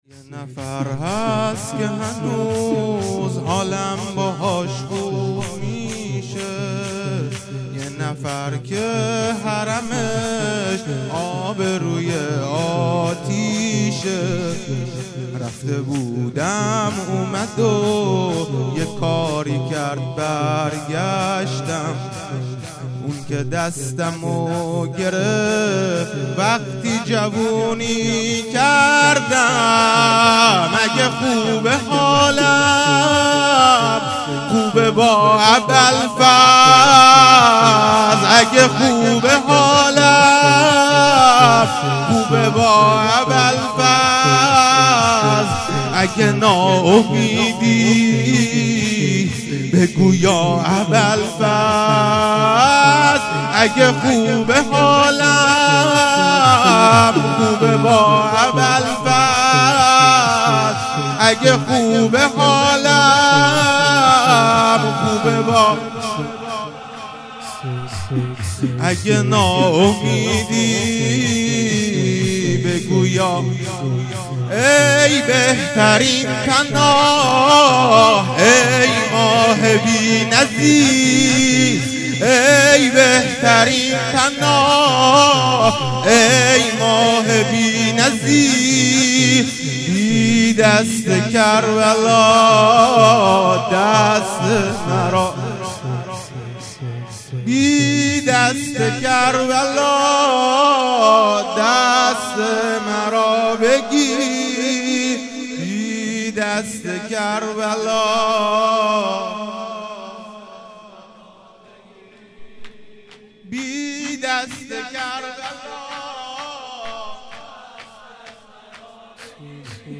شور _ بی دست کربلا دست مرا بگیر، هیات محبان امیرالمومین علی(ع) ، قدمگاه امام رضا(ع)